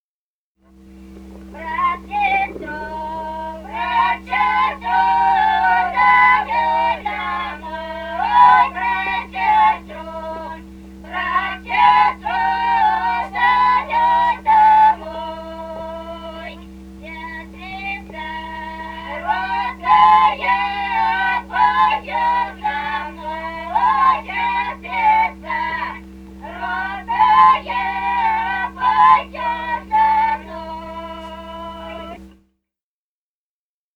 Русские народные песни Красноярского края.
«Брат сестру зовёт домой» (свадебная). с. Денисово Дзержинского района. Пела группа колхозниц